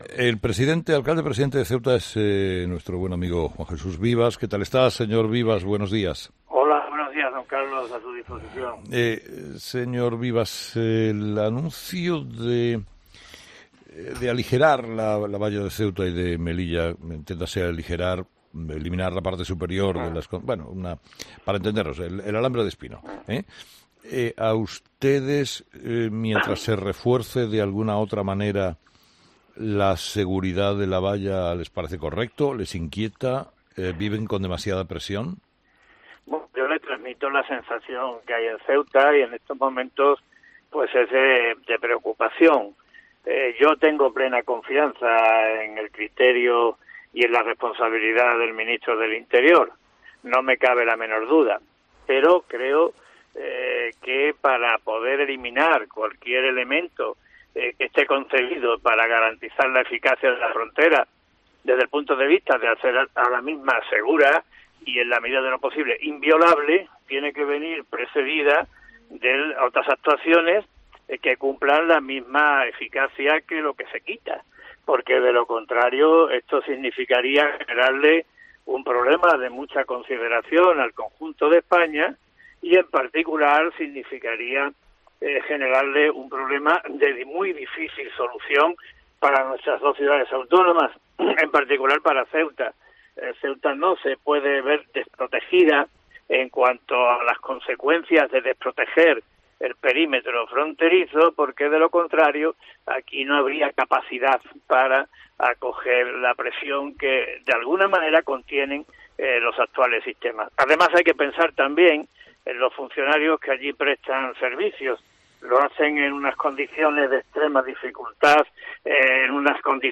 El presidente de la Ciudad Autónoma de Ceuta, Juan Jesús Vivas (PP) ha explicado en 'Herrera en COPE' que se ha tomado el anuncio de que el ministerio del Interior quiera retirar las concertinas de las vallas de Ceuta y Melilla con “preocupación”.